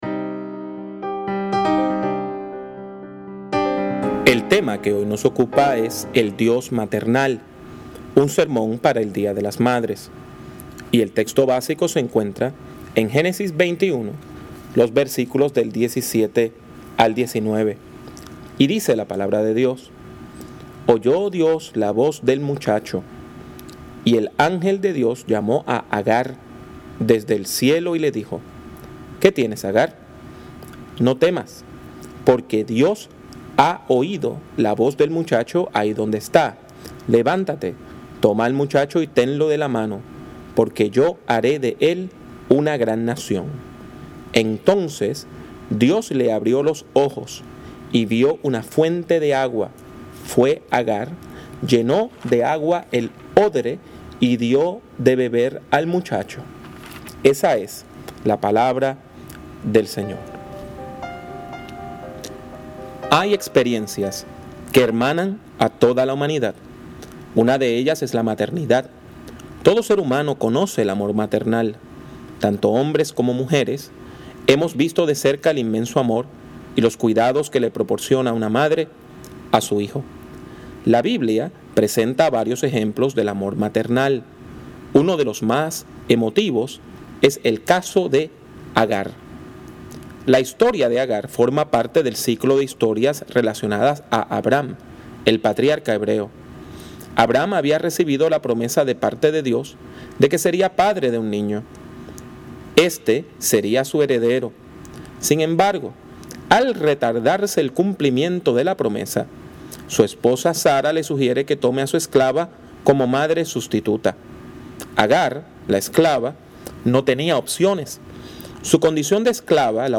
Área: Sermón en ocasión del día de las madres